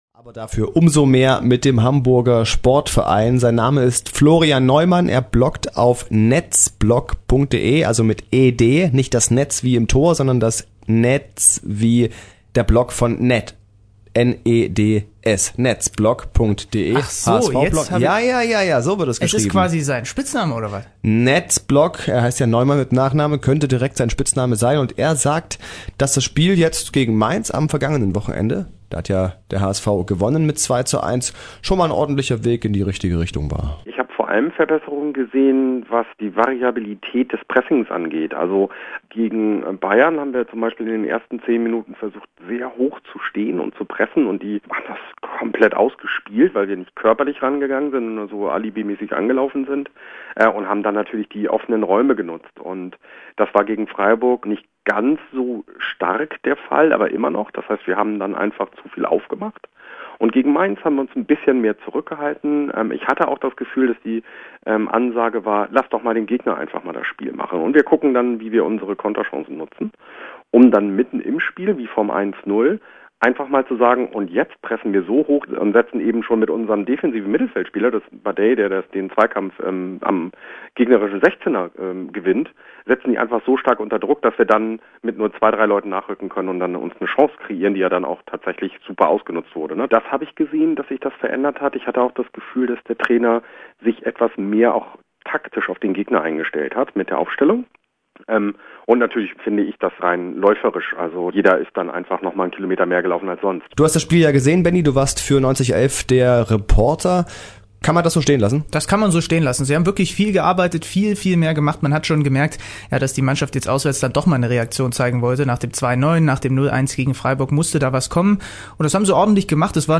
Mein Interview mit 90elf: